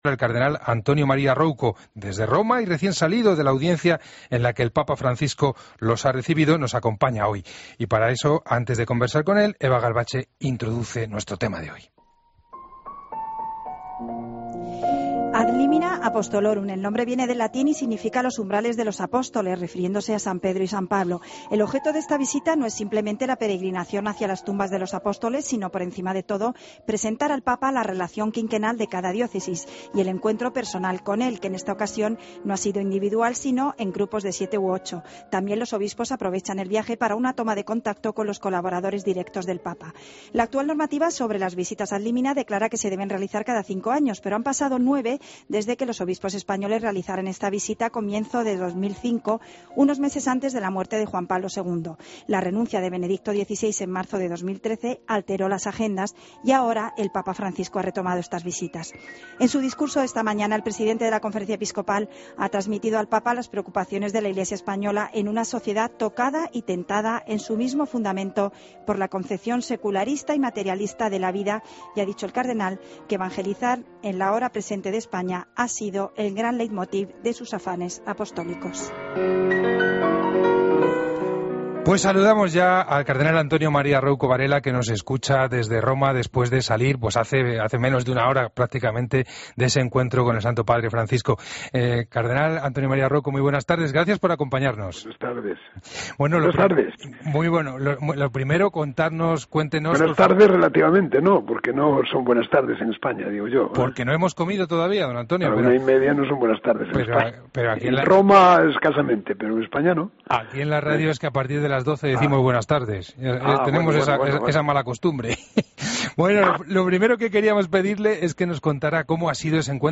Escucha la entrevista completa a monseñor Rouco Varela en 'El Espejo'